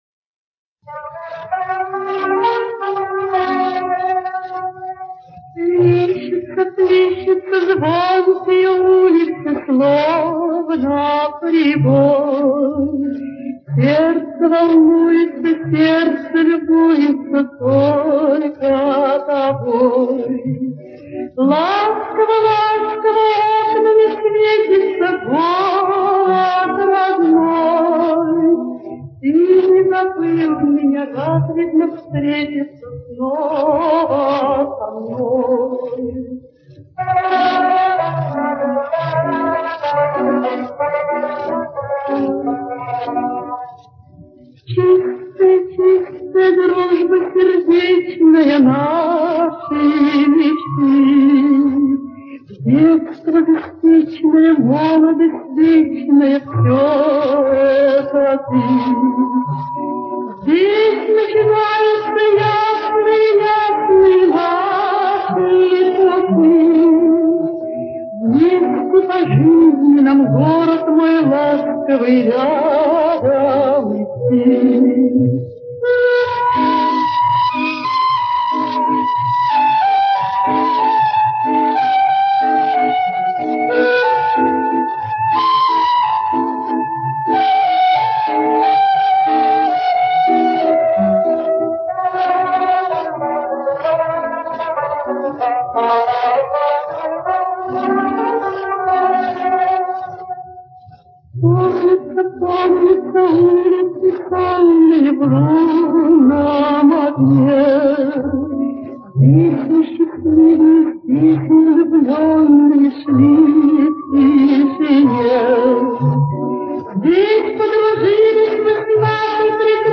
Ещё одна песня с "ребер".